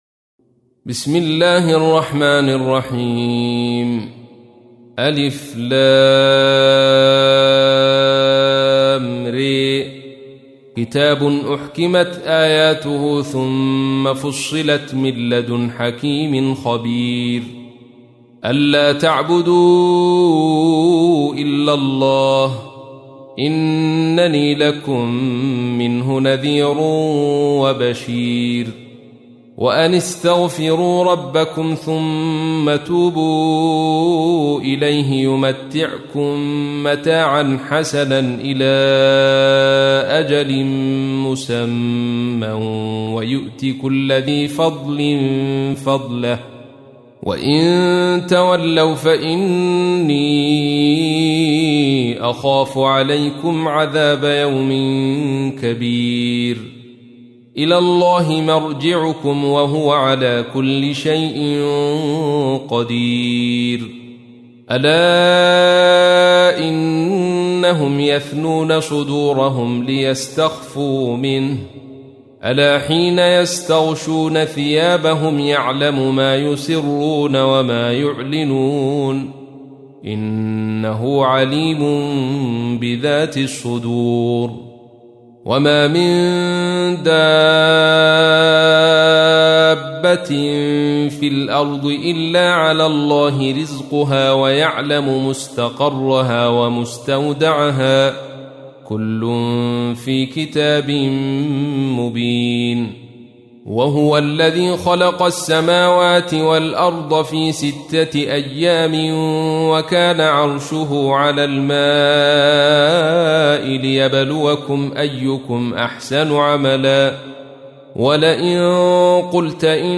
تحميل : 11. سورة هود / القارئ عبد الرشيد صوفي / القرآن الكريم / موقع يا حسين